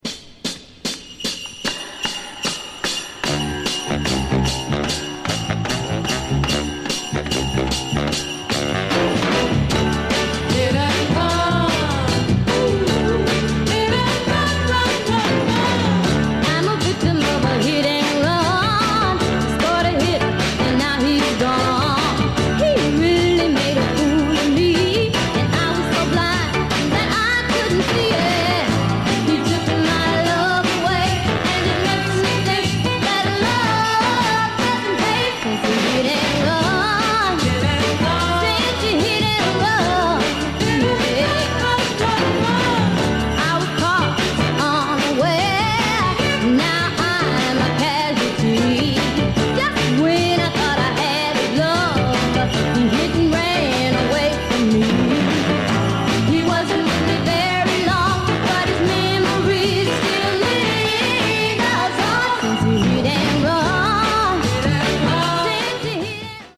For lovers of that classic Northern-Soul sound.